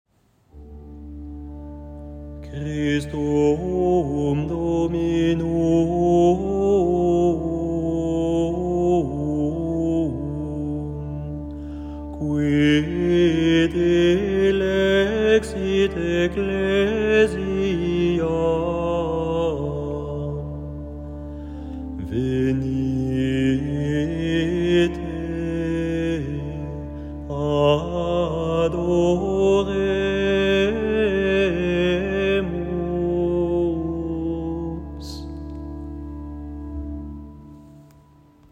Antienne invitatoire II : Christum Dominum [partition LT]